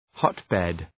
Προφορά
{‘hɒt,bed}